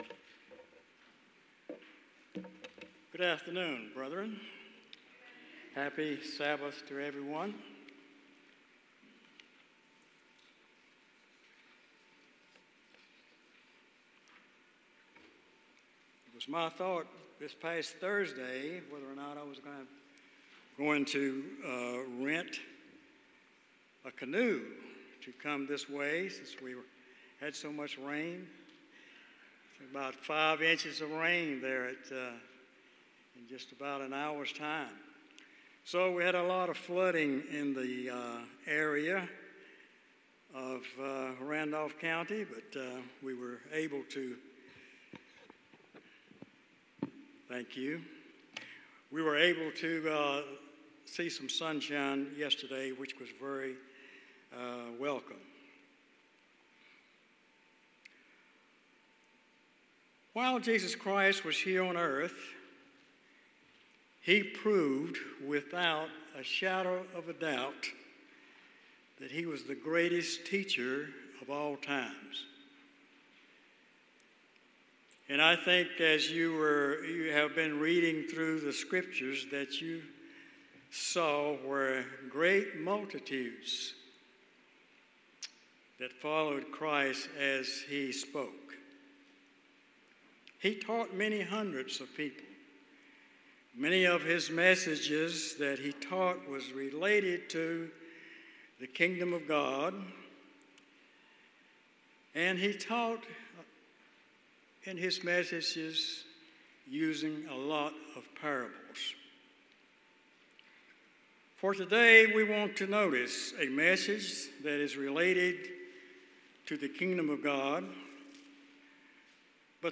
Given in Raleigh, NC